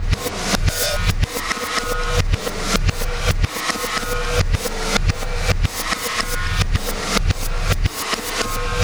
Black Hole Beat 16.wav